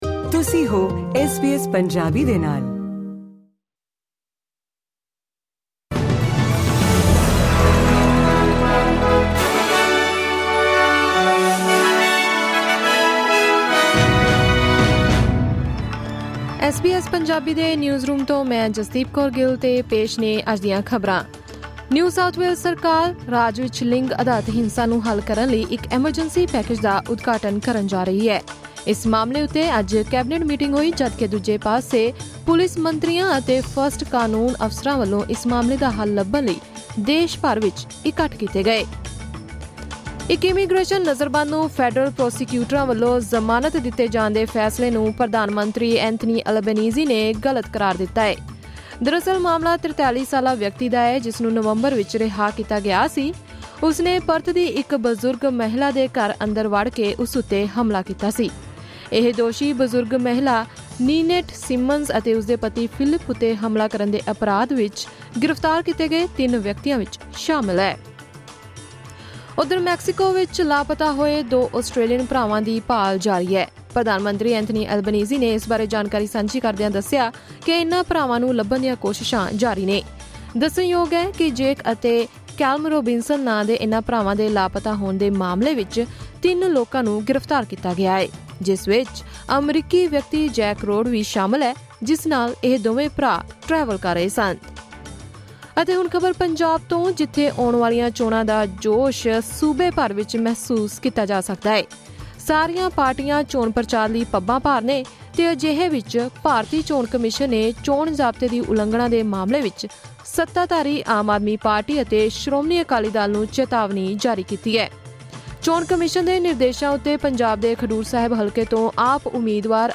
ਐਸ ਬੀ ਐਸ ਪੰਜਾਬੀ ਤੋਂ ਆਸਟ੍ਰੇਲੀਆ ਦੀਆਂ ਮੁੱਖ ਖ਼ਬਰਾਂ: 3 ਮਈ, 2024